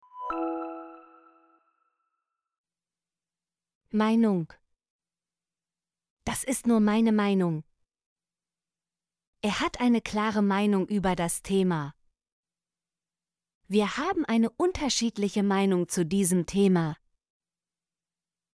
meinung - Example Sentences & Pronunciation, German Frequency List